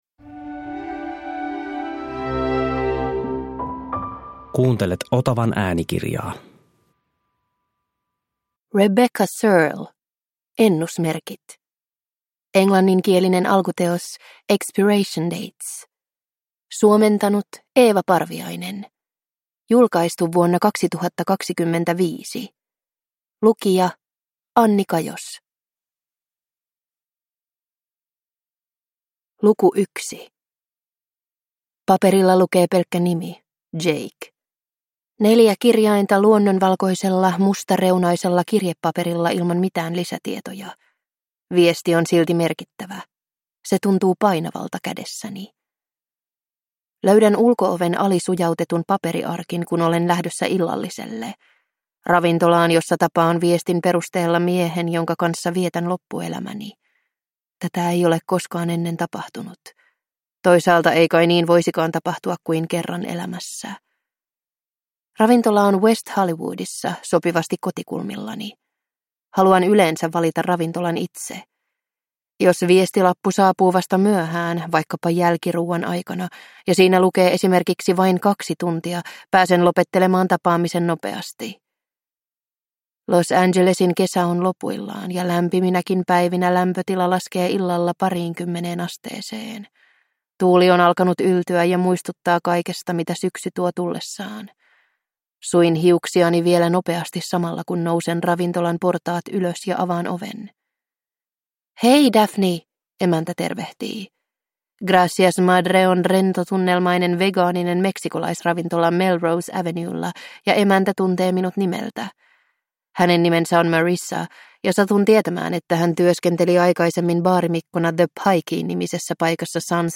Ennusmerkit – Ljudbok